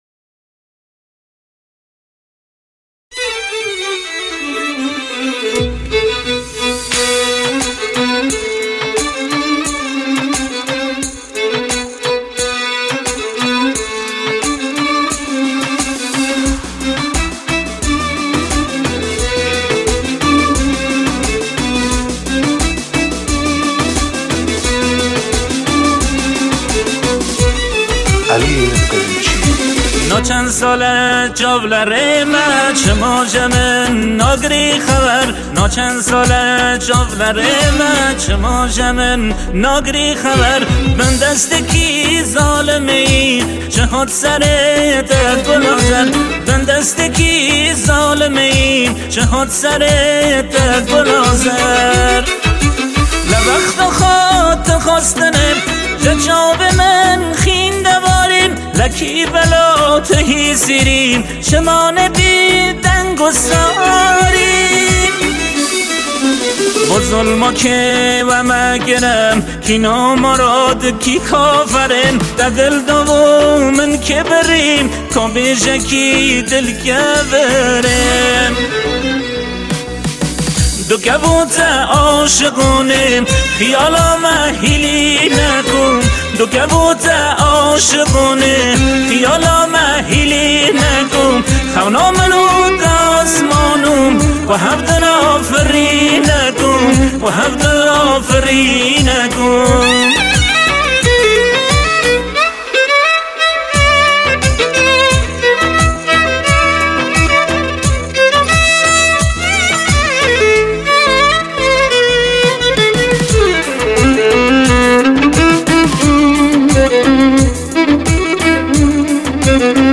اهنگ کرمانجی